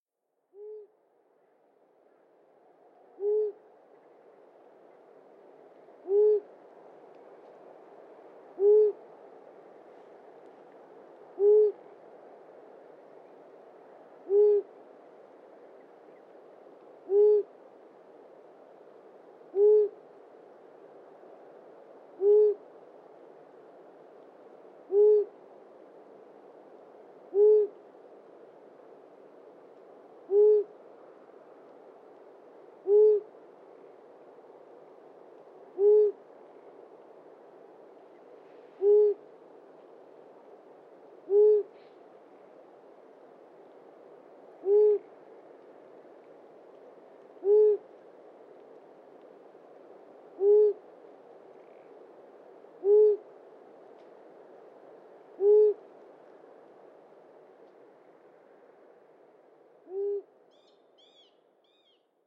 moyenduc.mp3